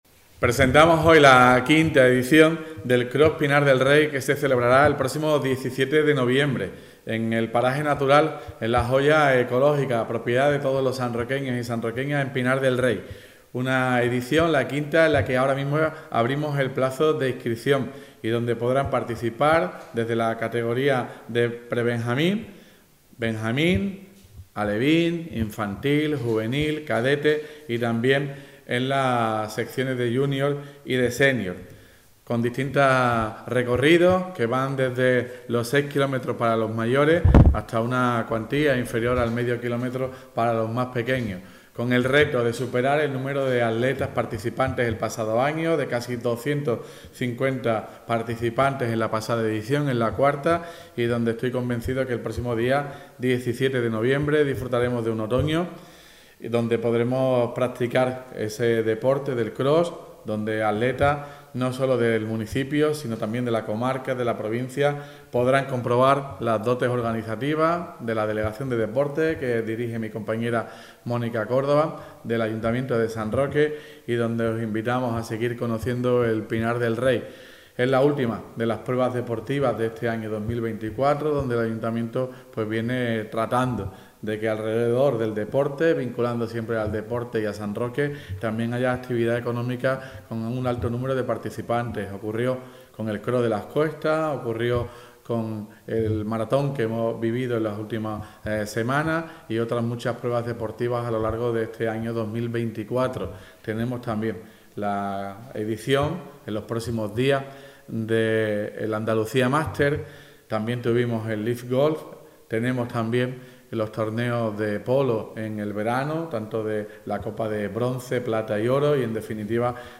PRESENTACIÓN V CROSS PINAR DEL REY TOTAL ALCALDE.mp3